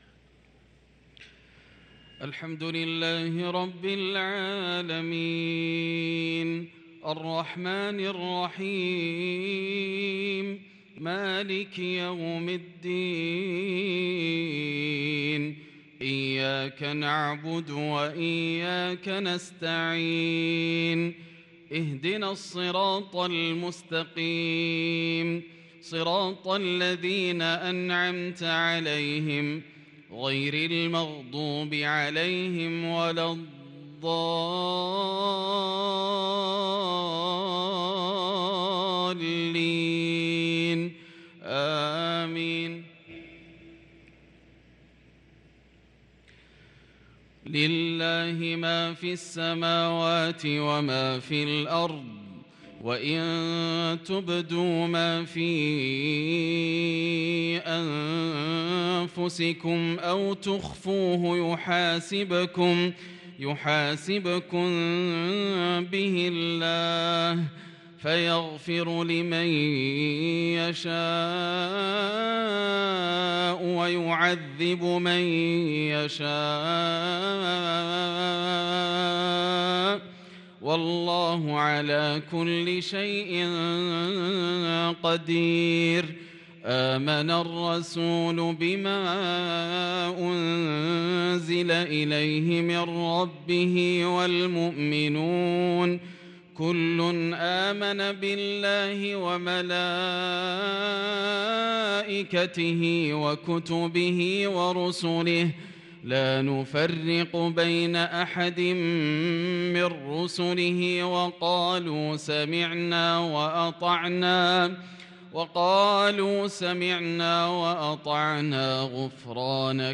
صلاة المغرب للقارئ ياسر الدوسري 24 ذو القعدة 1443 هـ
تِلَاوَات الْحَرَمَيْن .